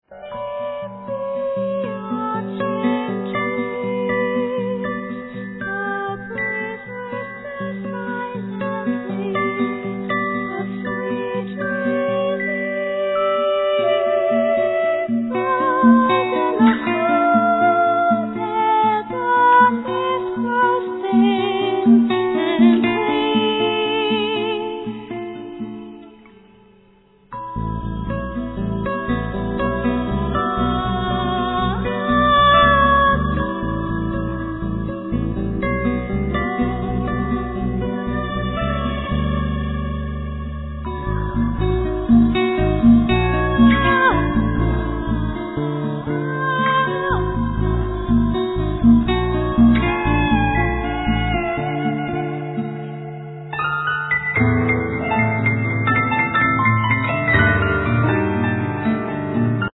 Violin
Flute
Drums, Percussions, Bass, Vocals ,All other instruments